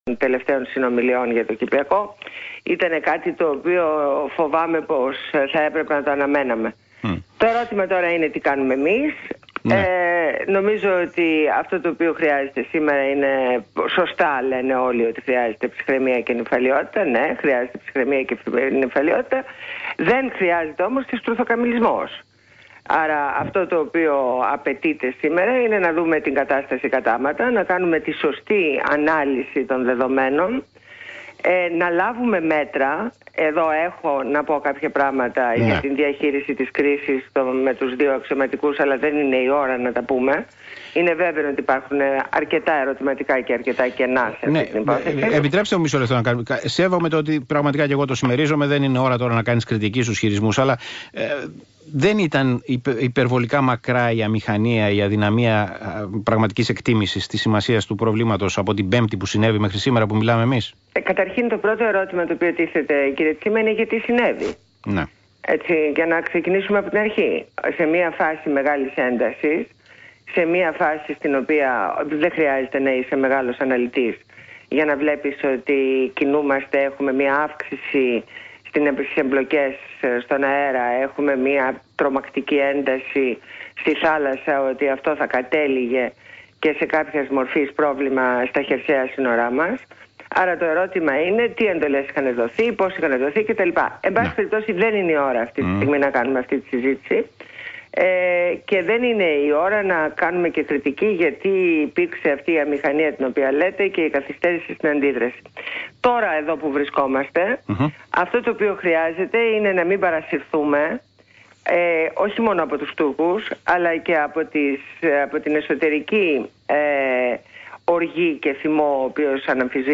Συνέντευξη στο ραδιόφωνο του ΣΚΑΪ